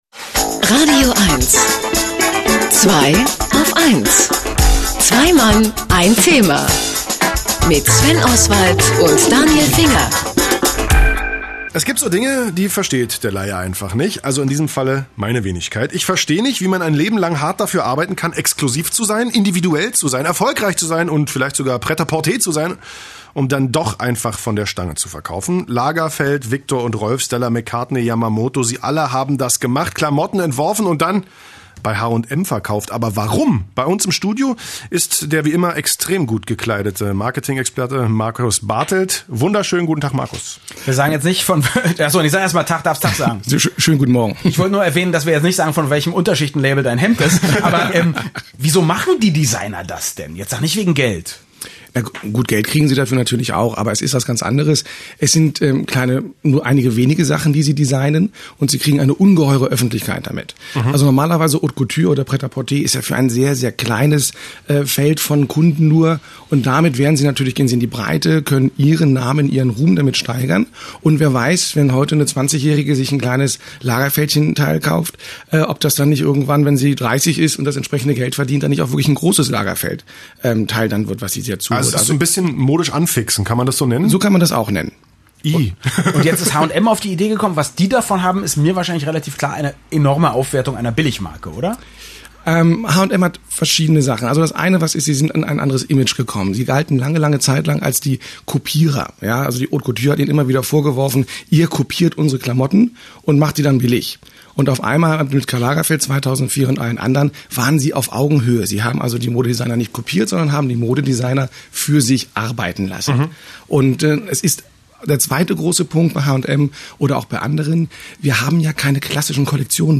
Und wieder begeben wir uns auf die Erinnerungsstraße und entdecken längst vergessene Perlen meiner radioeins-Interviews wieder….